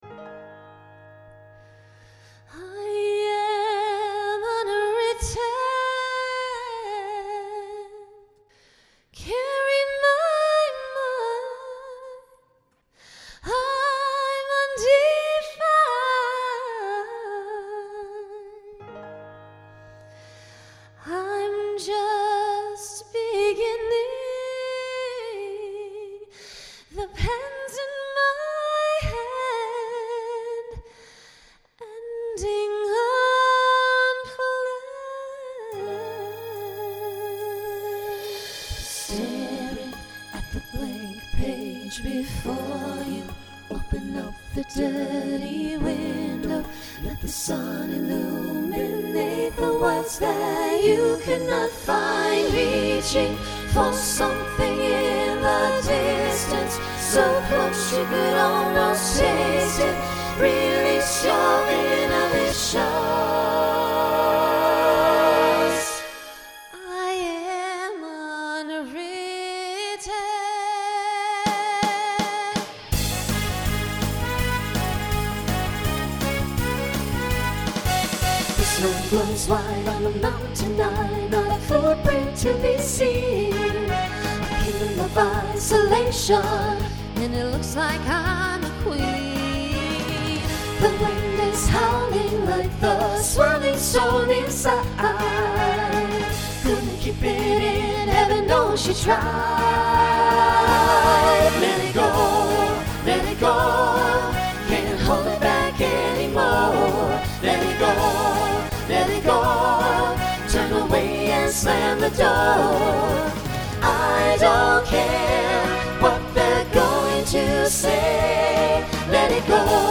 Voicing SATB Instrumental combo Genre Pop/Dance , Rock